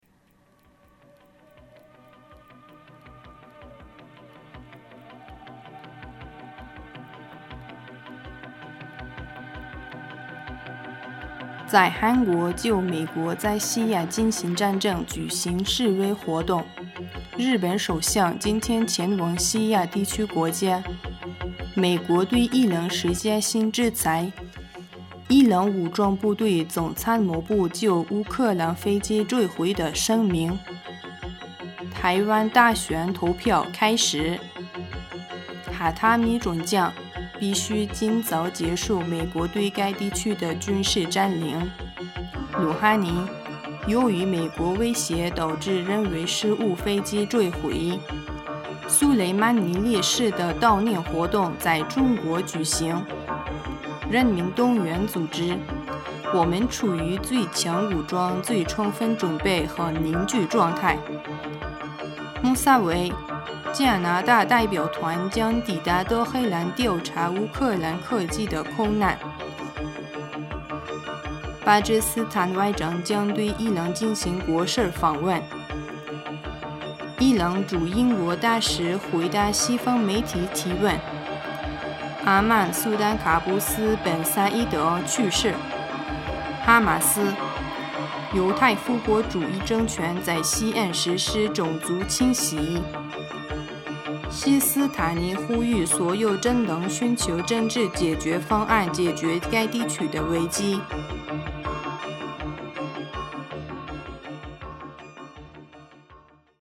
2020年1月11日 新闻